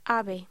Locución